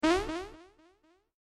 fix-it-felix-jump_24957.mp3